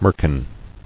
[mer·kin]